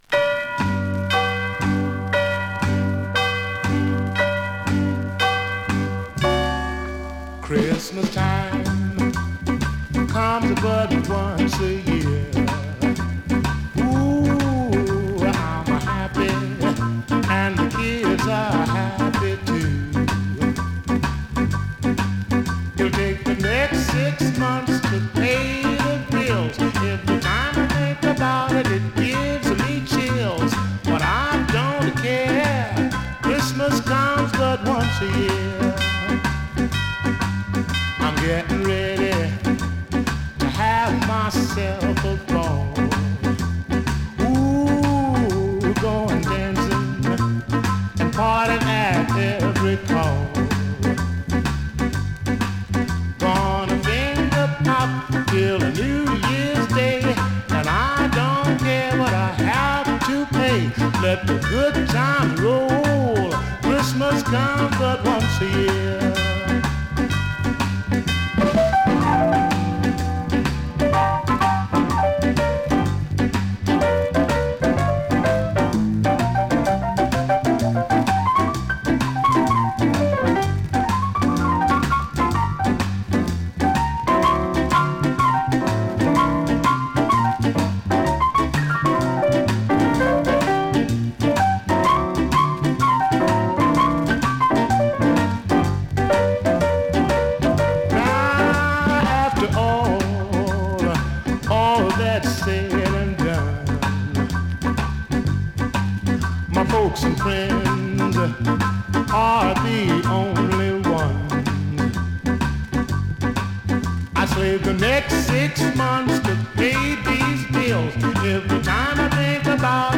現物の試聴（両面すべて録音時間５分２５秒）できます。